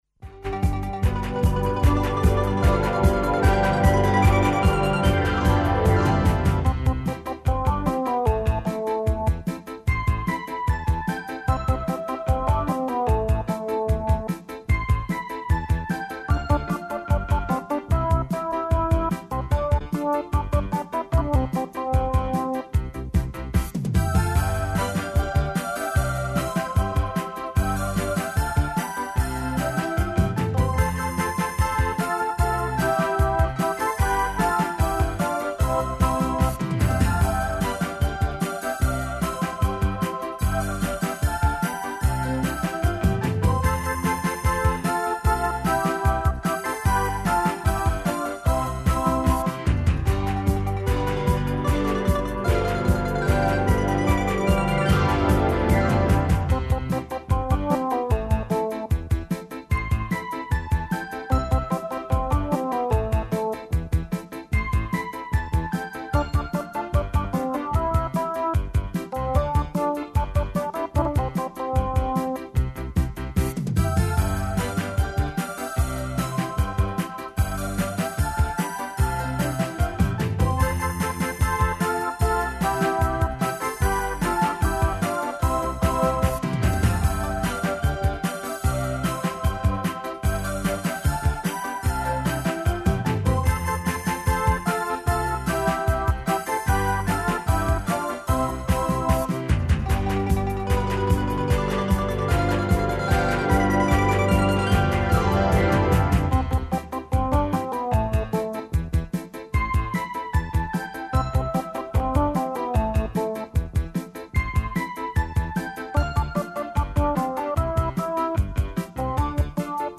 Саговорници су нам ученици основних школа различитих узраста, као и одрасли који такође са њима полазе у још једну годину школовања.